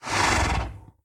Sound / Minecraft / mob / horse / idle3.ogg